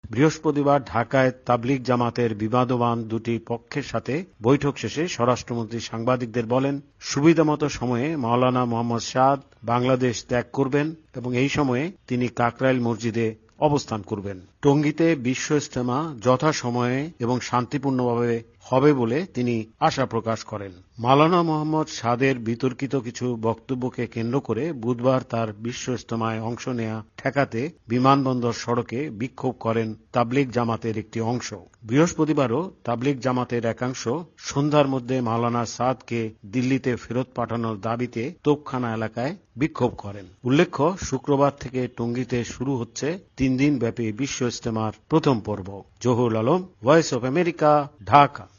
এ বিষয়ে ঢাকা থেকে বিস্তারিত জানিয়েছেন সংবাদদাতা